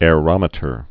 (â-rŏmĭ-tər)